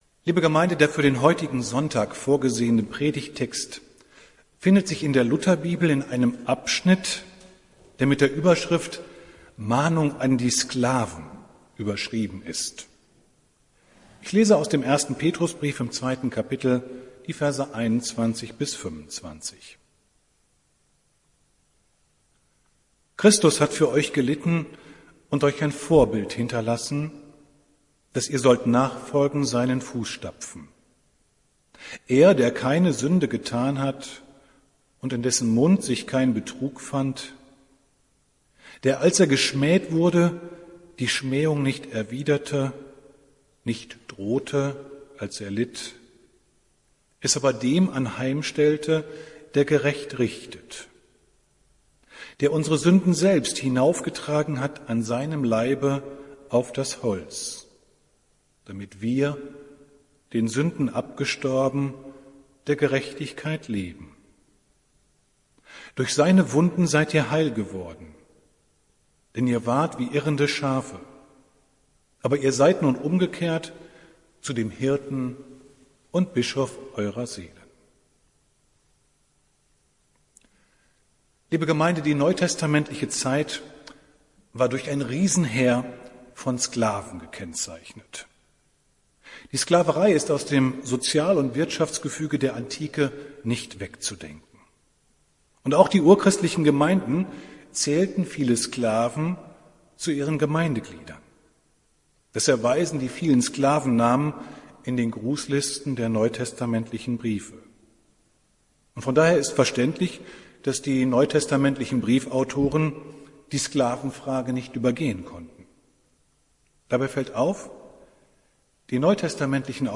Predigt des Gottesdienstes vom 26. April 2020